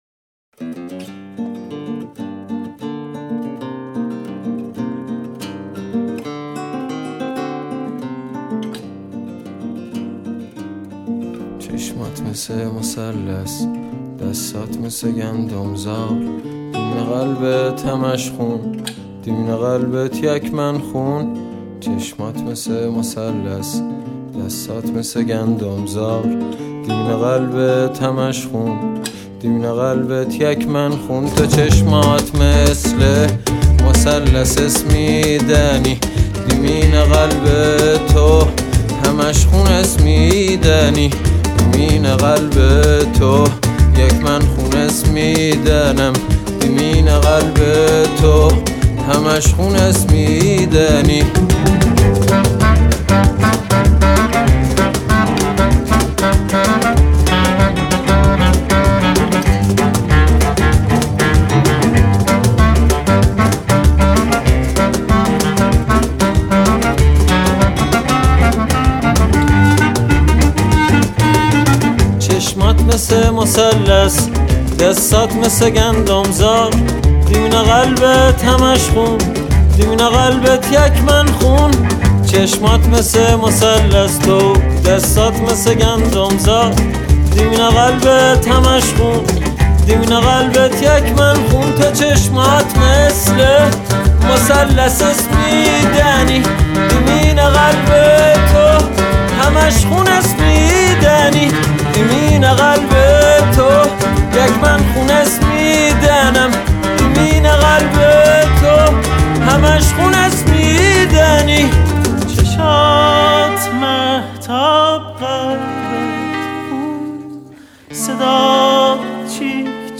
double bass, guitar, cello, accordion, clarinet and voice